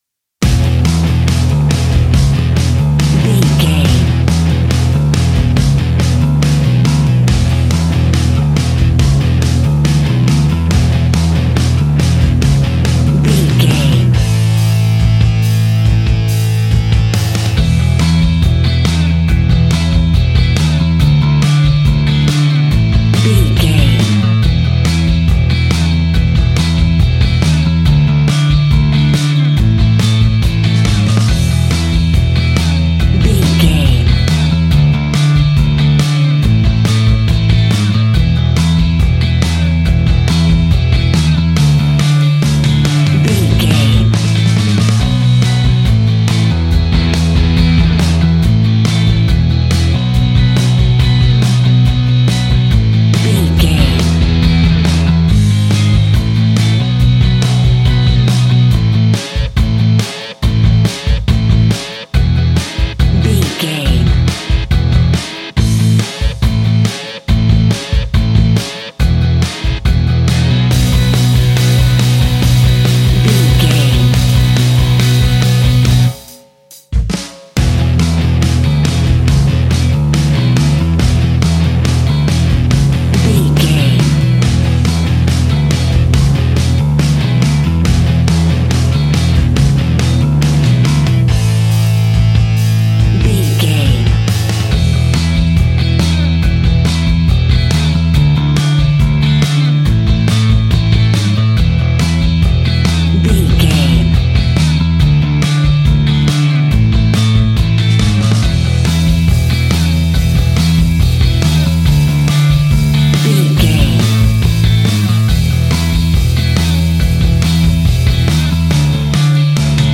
Ionian/Major
indie pop
fun
energetic
uplifting
instrumentals
upbeat
groovy
guitars
bass
drums
piano
organ